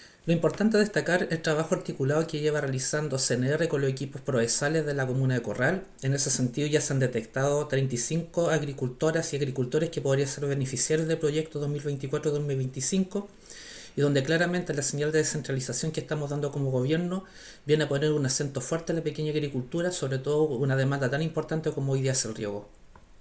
Proyecto-CNR-Corral-Seremi.mp3